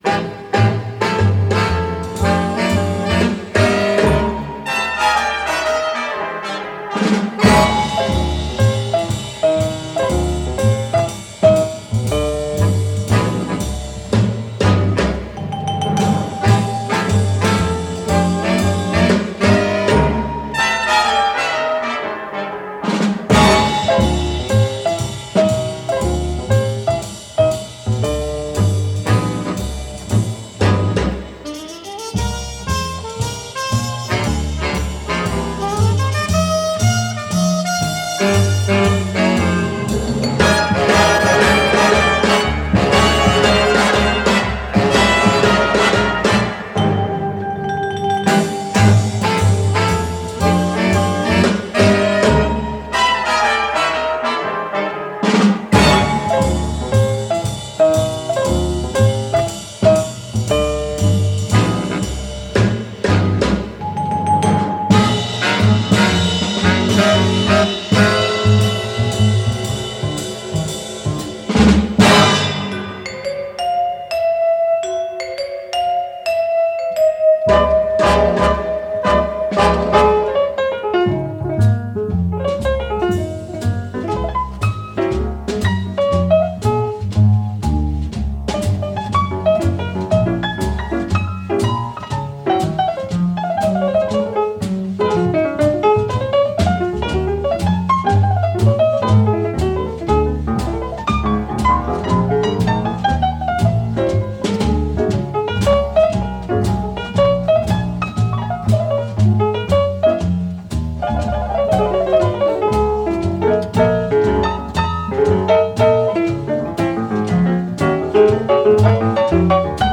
Жанр: Bossa Nova.